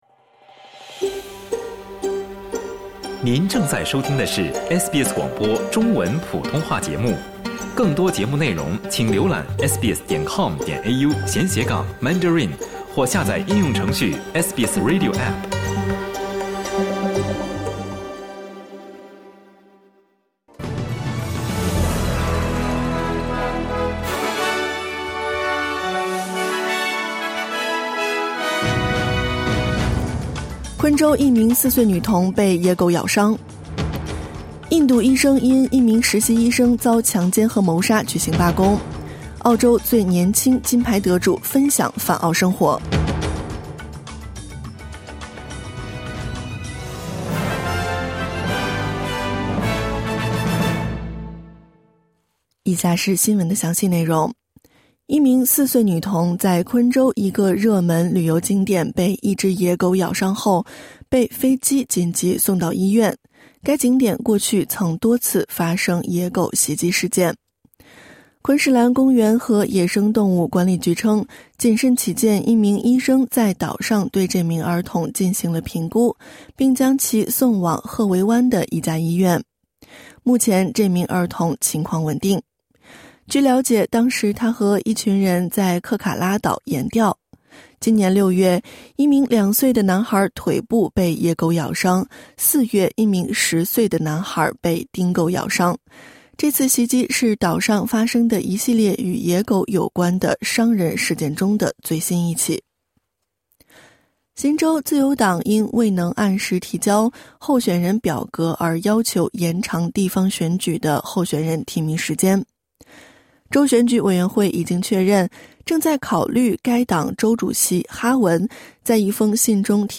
SBS早新闻（2024年8月18日）